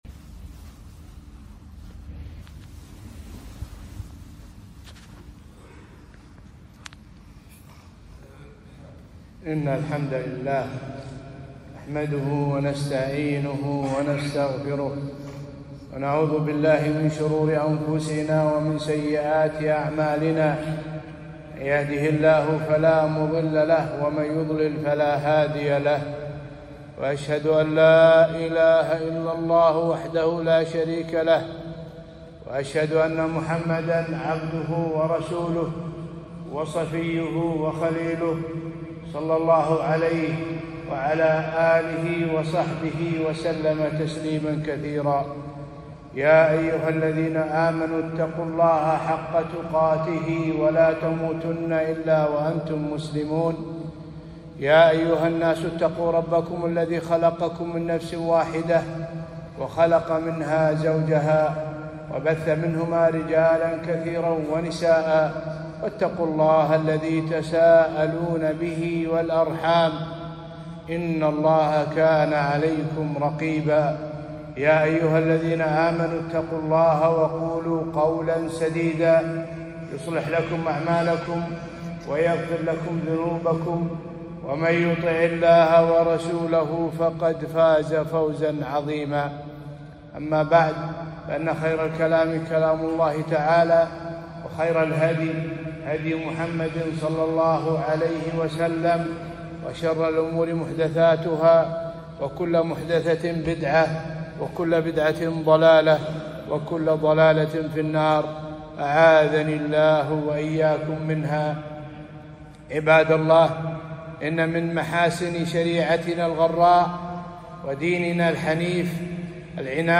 خطبة - فضل خلق كف الأذى عن الناس